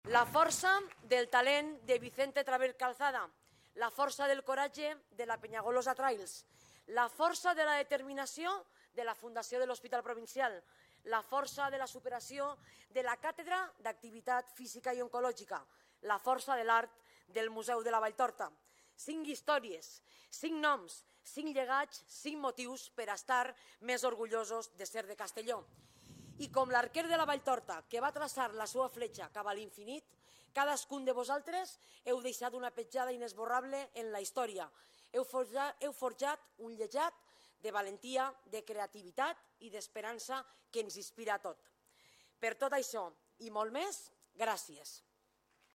Presidenta-Marta-Barrachina-Dia-de-la-Provincia-3.mp3